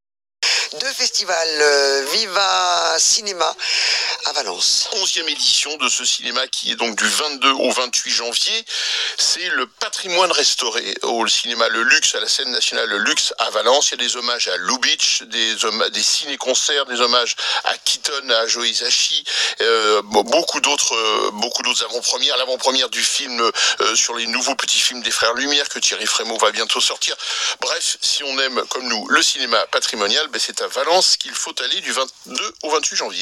↳ Interview réalisée par La Radio du Cinéma